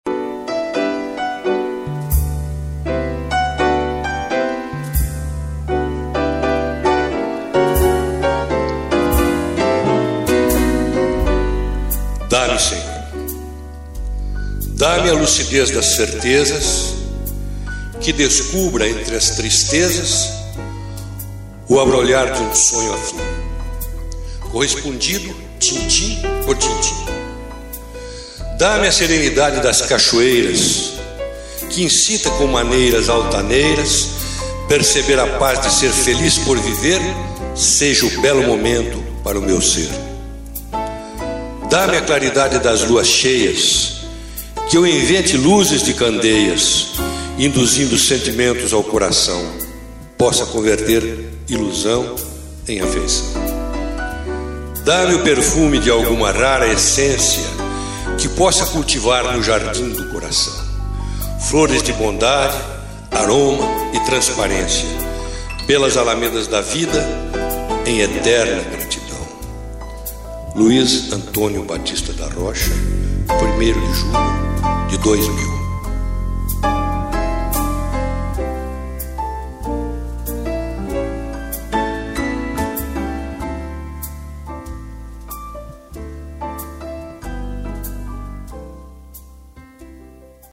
interpretação do texto
música fundo